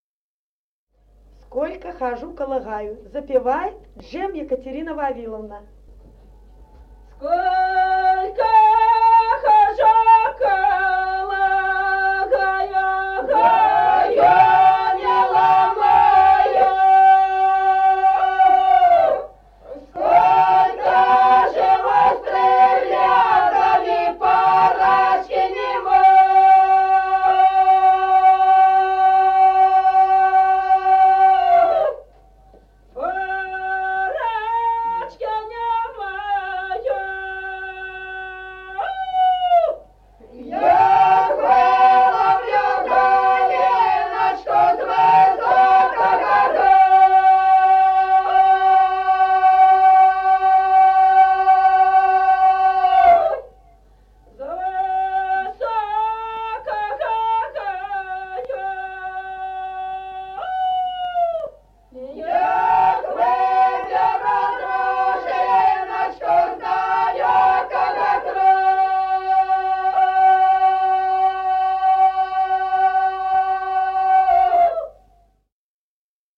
Песни села Остроглядово. Сколько хожу коло гаю (петровочная).